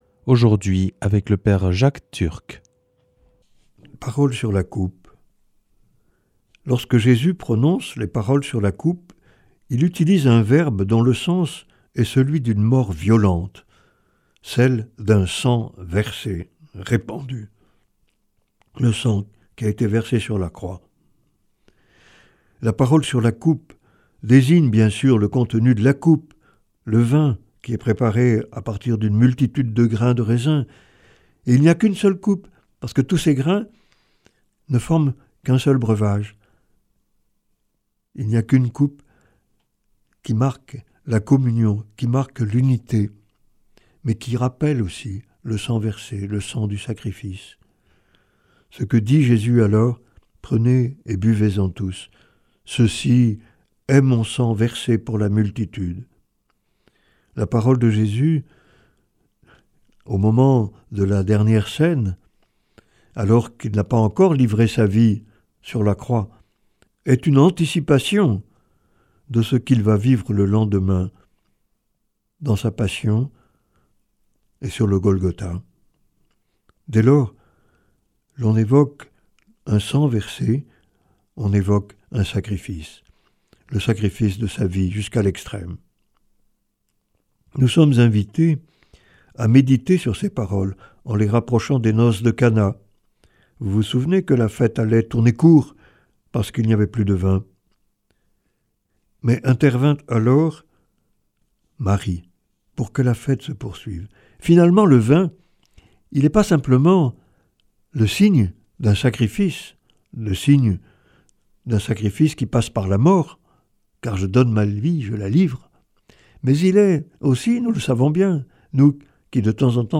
Enseignement Marial du 06 juin